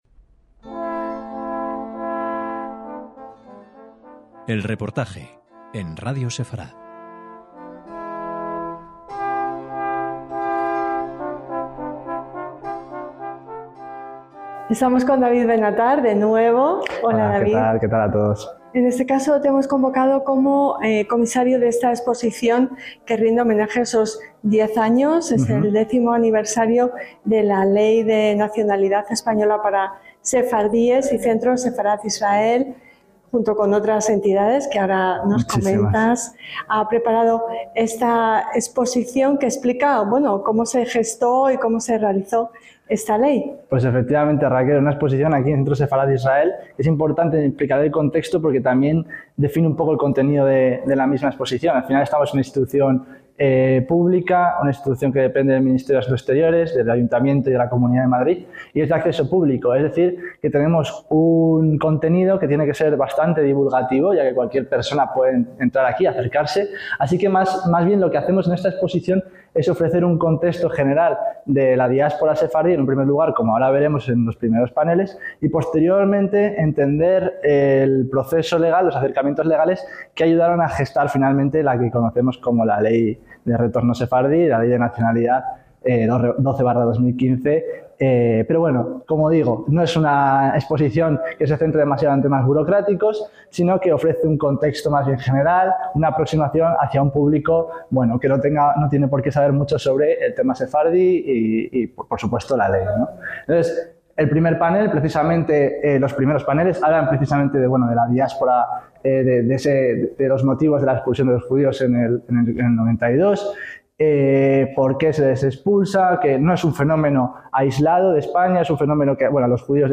EL REPORTAJE - En 2015, España dio un paso histórico al reconocer, mediante la Ley 12/2015, el derecho a obtener la nacionalidad española a los descendientes de los judíos expulsados en 1492.